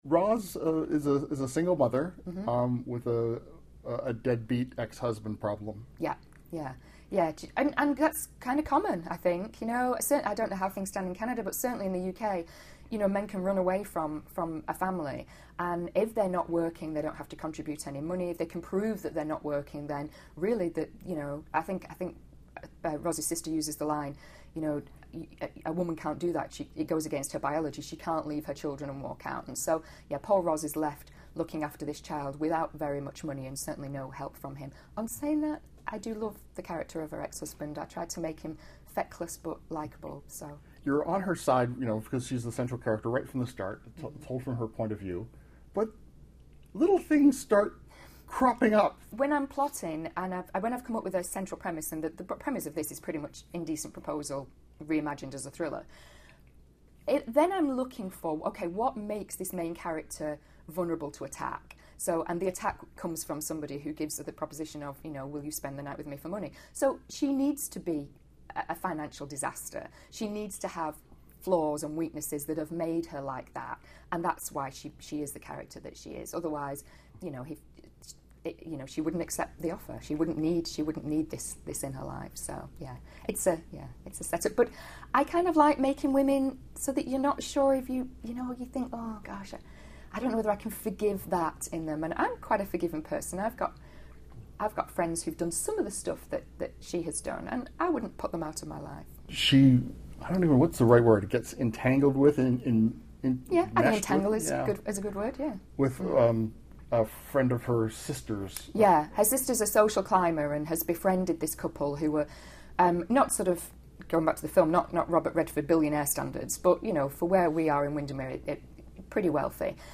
Recording Location: Toronto
Type: Interview